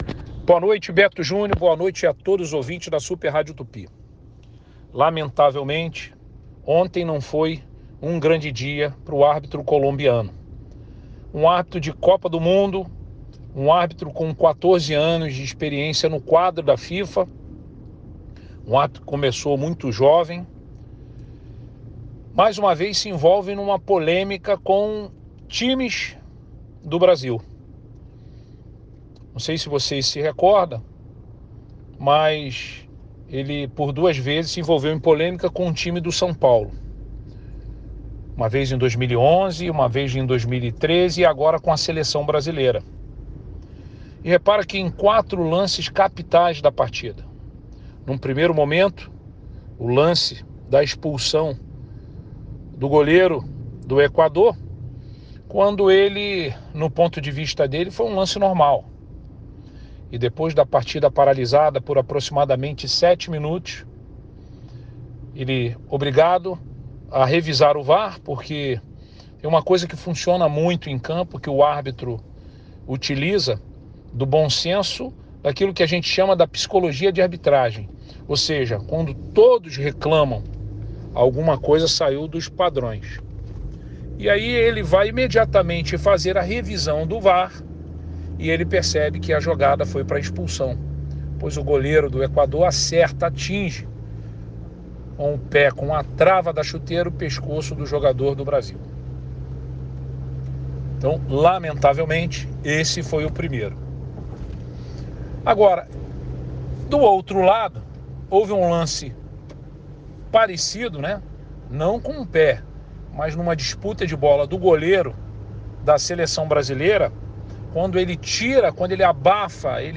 Confira a declaração de Gutemberg Fonseca ao Giro Esportivo, desta sexta-feira (28):